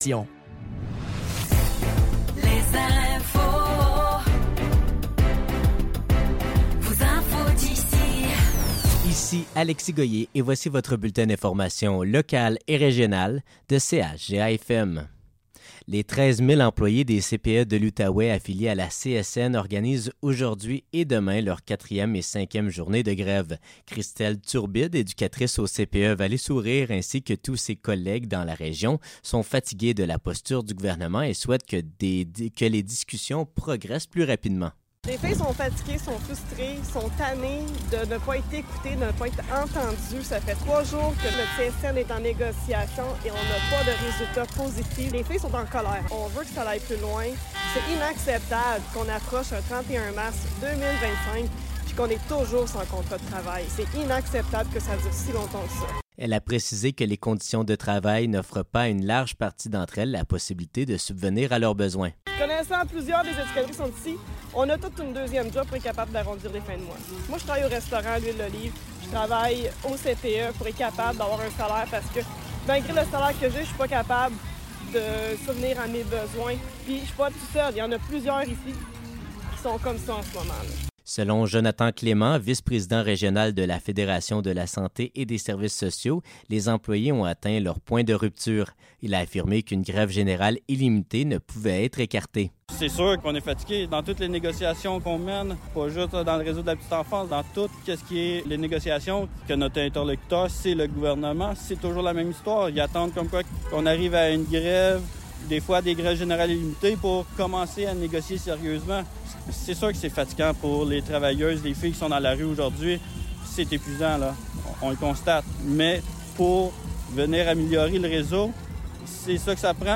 Nouvelles locales - 18 mars 2025 - 12 h